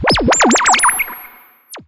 Index of /musicradar/sci-fi-samples/Theremin
Theremin_FX_16.wav